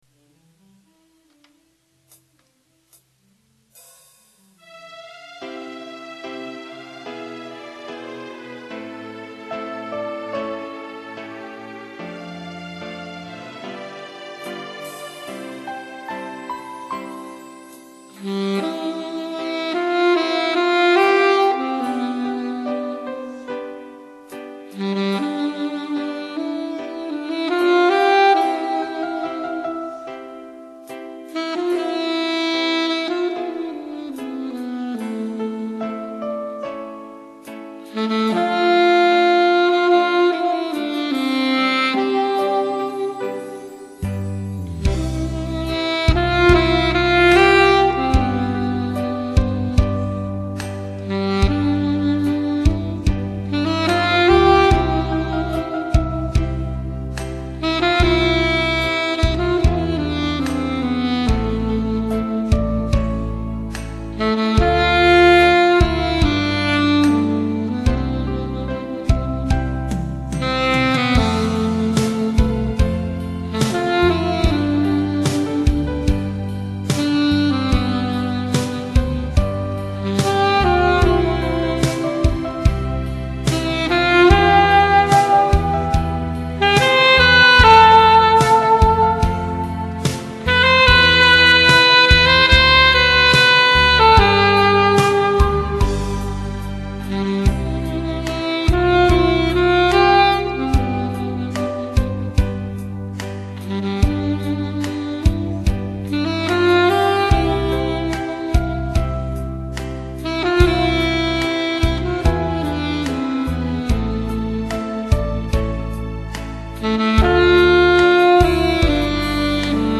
악기연주곡